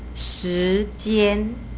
shi2-jian1 = two times the word time, thus meaning time span.